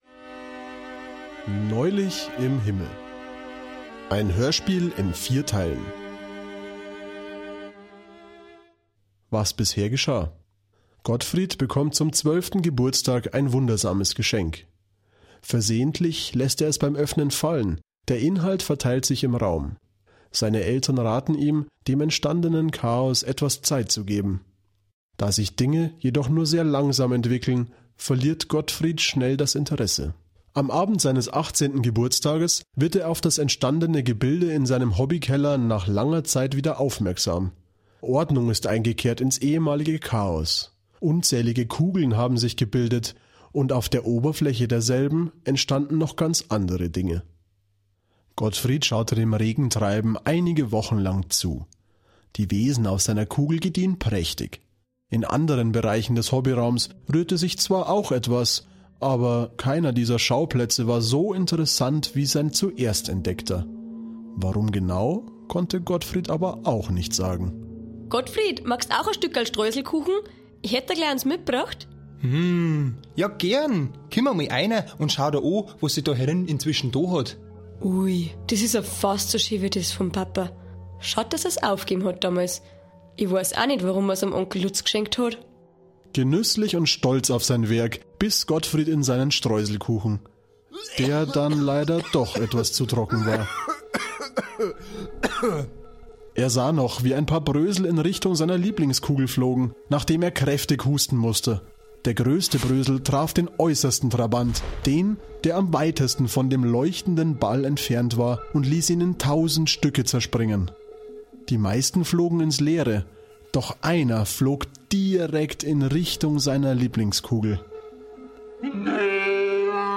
7. Hörspiel
Hoerspiel_NeulichImHimmel_Folge3.mp3